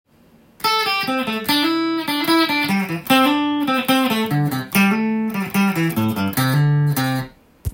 エレキギターで弾ける【歌うAマイナーペンタトニックスケール】シーケンスパターン【オリジナルtab譜】つくってみました
【歌うAマイナーペンタトニックスケール】シーケンスパターン